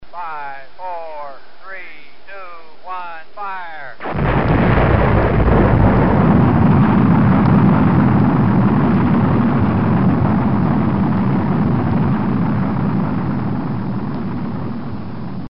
Отсчёт времени до запуска и звук старта